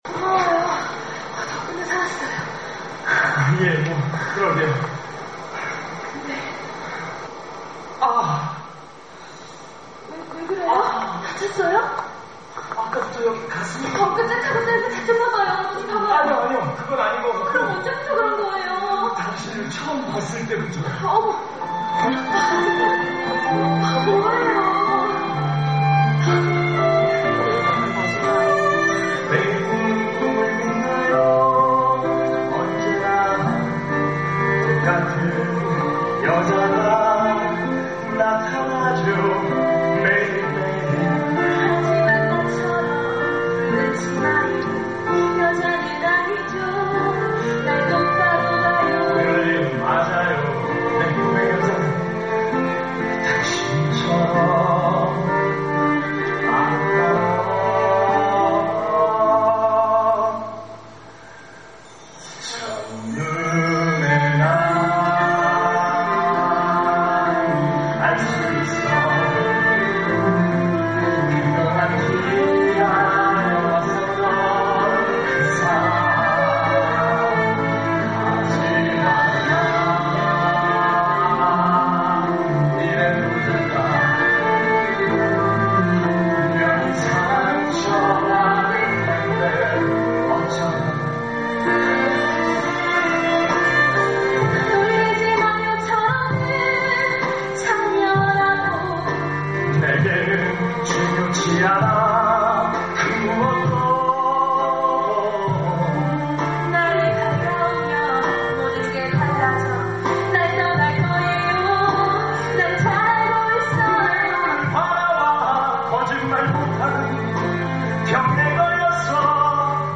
第一季公演精彩现场唱段